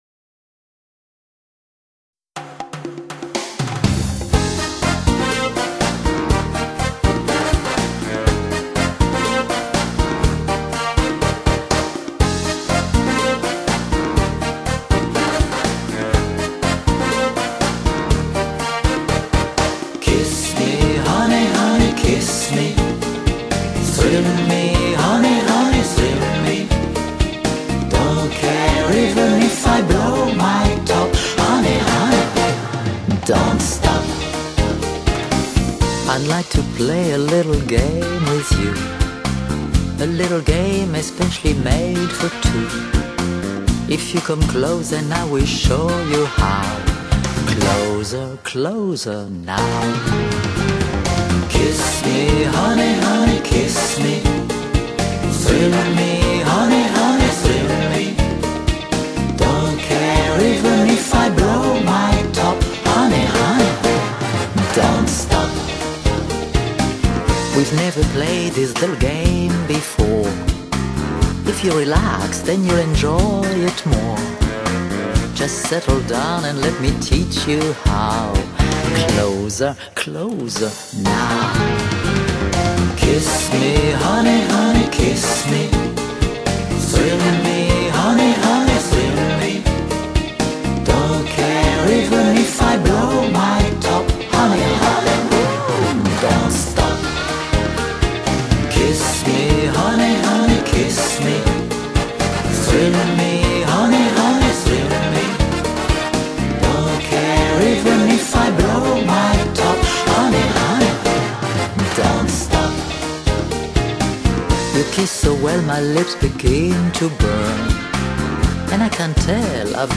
Styles: Jazz, Vocal Jazz, Chanson, Jazz-Pop